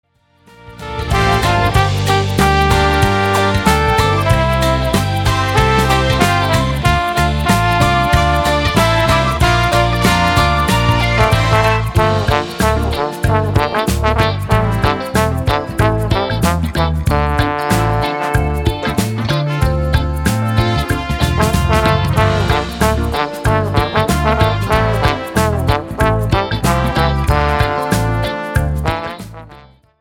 POP  (3.49)